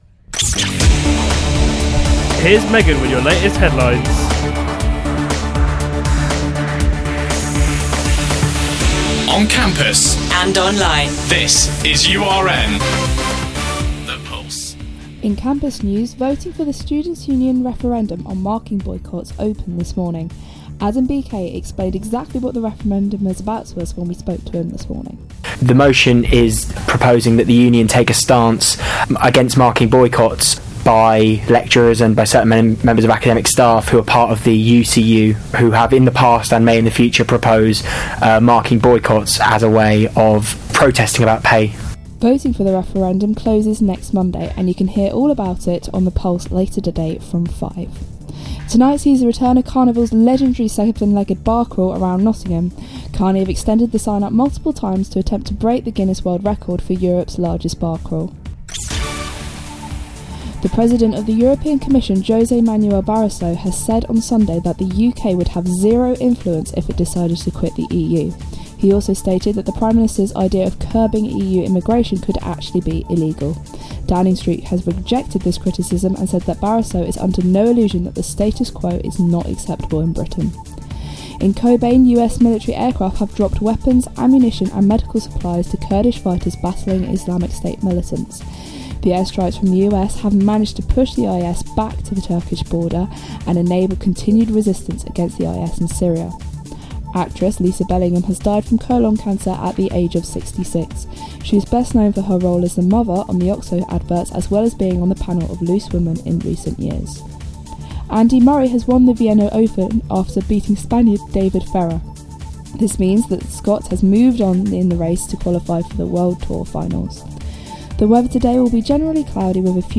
Your Latest Headlines on URN
All of your latest headlines on URN